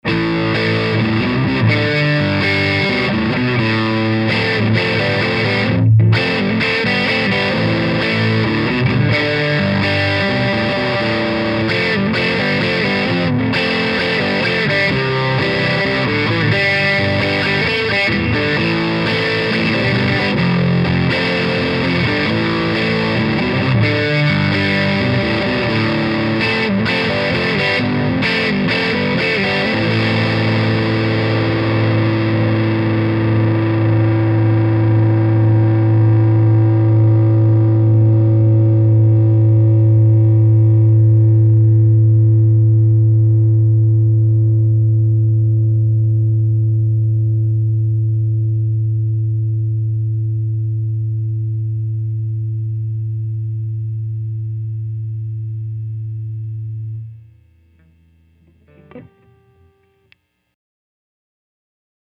Turning up the gain on my amp, and getting lots of power tube distortion really brings out the true character of the Medusa 150.
That’s a fairly simple progression, but I chose it because it’s a good test of how clear the speaker would be in a high-gain situation playing low on the fretboard, which almost always has the potential of muddying your tone when you use speakers that aren’t well-defined.